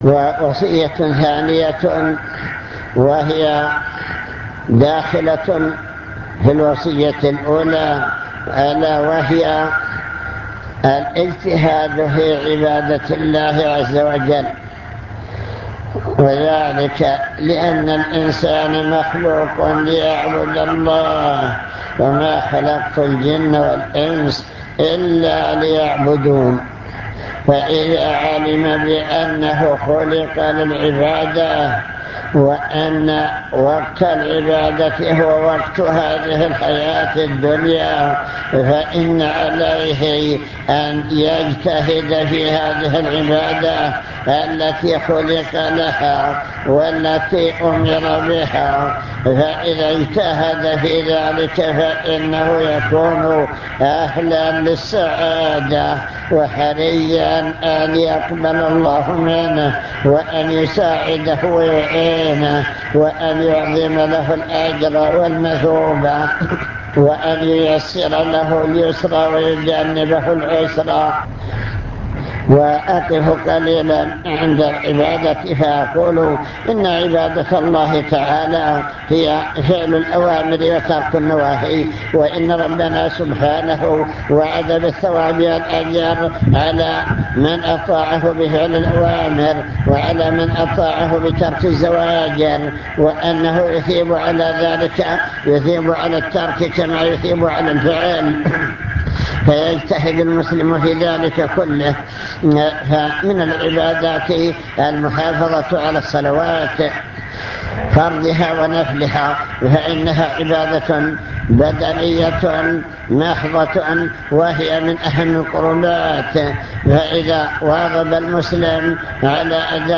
المكتبة الصوتية  تسجيلات - محاضرات ودروس  محاضرة بعنوان من يرد الله به خيرا يفقهه في الدين